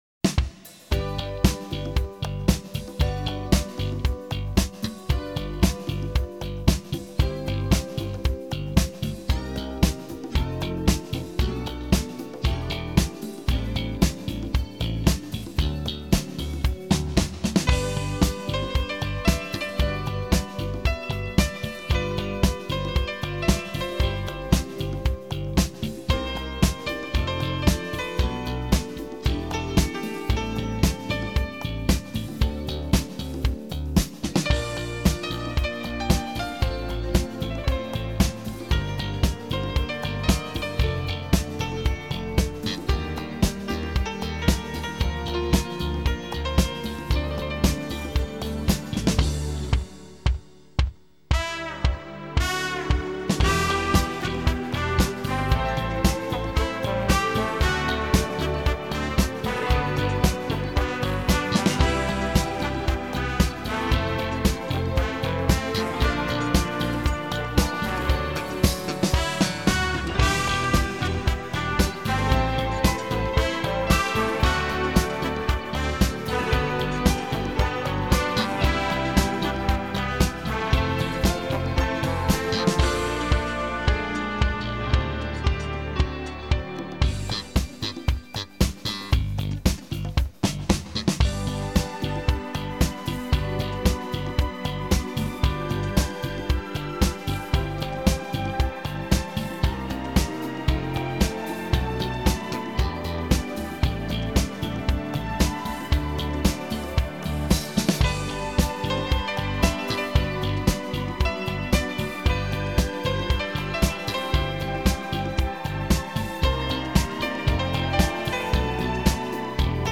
让我们在轻快的鼓点中放松心情，享受生活吧